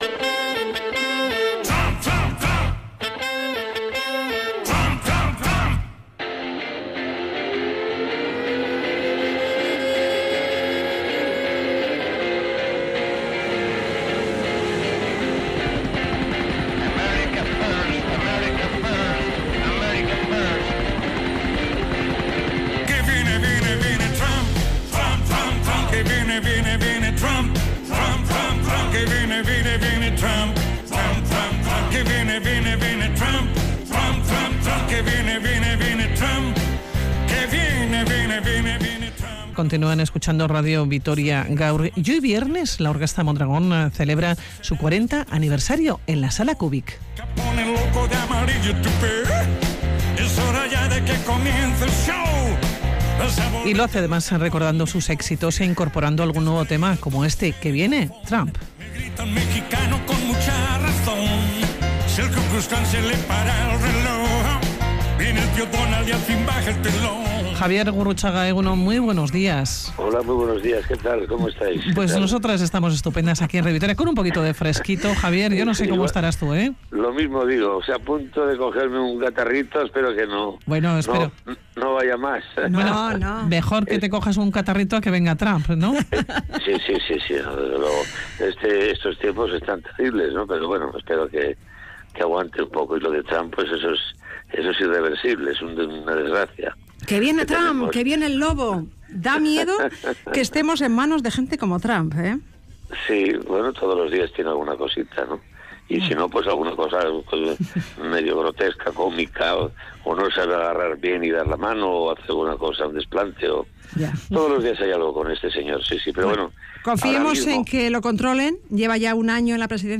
Audio: Y lo hace recordando sus éxitos e incorporando algún nuevo tema. En Radio Vitoria Gaur Magazine hemos hablado con Javier Gurrutxaga.